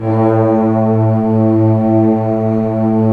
Index of /90_sSampleCDs/Roland - Brass, Strings, Hits and Combos/ORC_Orc.Unison p/ORC_Orc.Unison p